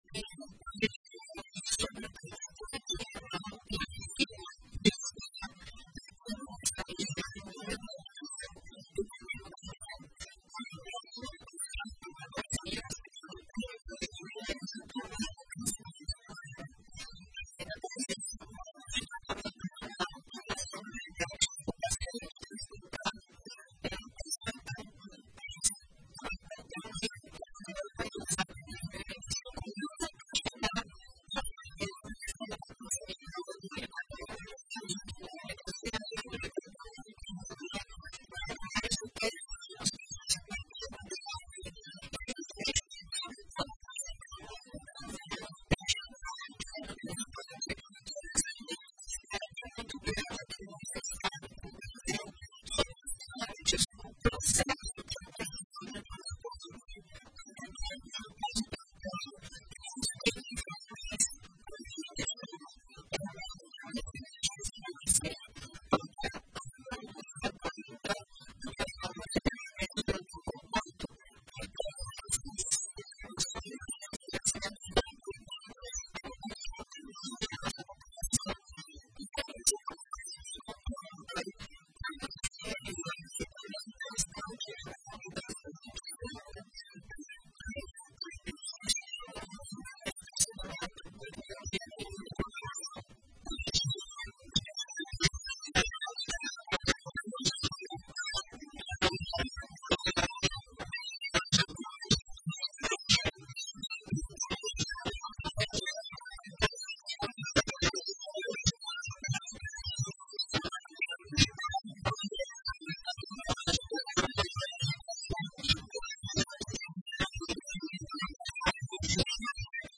Na mesma entrevista, a profissional respondeu a dúvidas frequentes sobre o transplante renal, como por exemplo, os cuidados exigidos no pós-operatório e quais diagnósticos indicam a necessidade de uma transplantação.